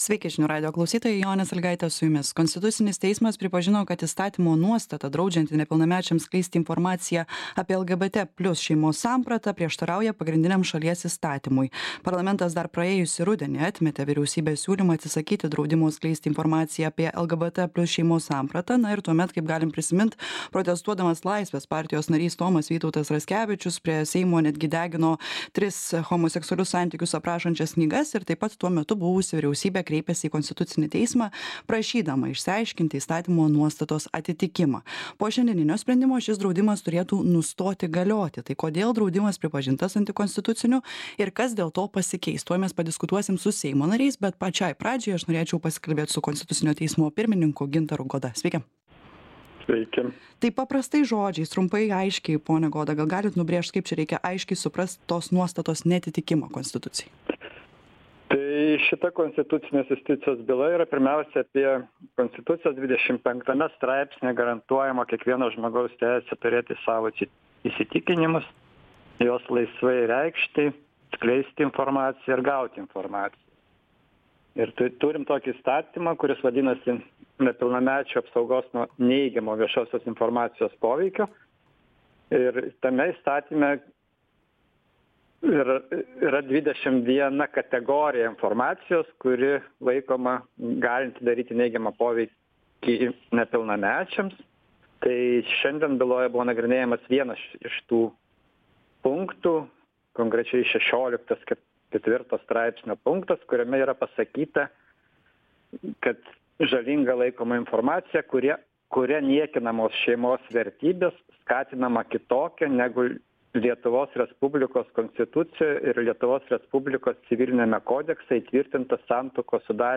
Laidoje dalyvauja Konstitucinio Teismo pirmininkas Gintaras Goda, Seimo Žmogaus teisių komiteto pirmininkas Laurynas Šedvydis ir Seimo narys Vytautas Sinica.
Aktualusis interviu